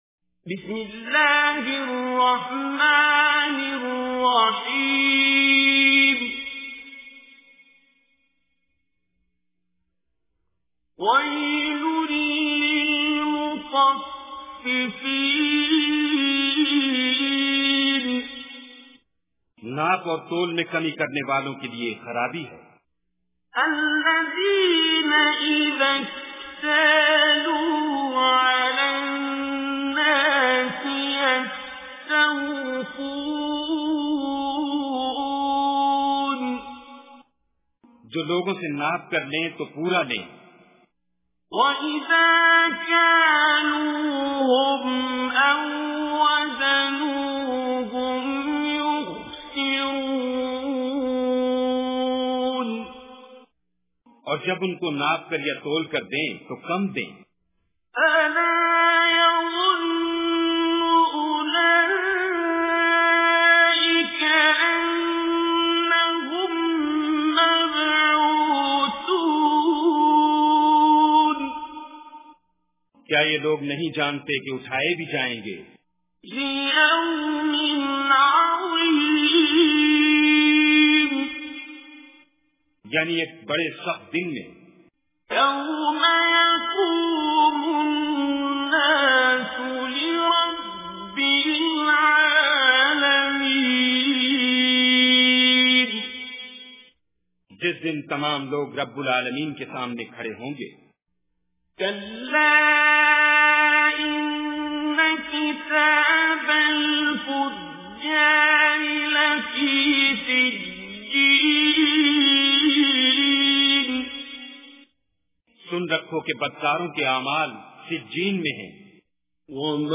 Surah Mutaffifin Recitation with Urdu Translation
Surah Al-Mutaffifin is 83rd chapter of Holy Quran. Listen online and download mp3 tilawat / recitation of Surah Al-Mutaffifin in the voice of Qari Abdul Basit As Samad.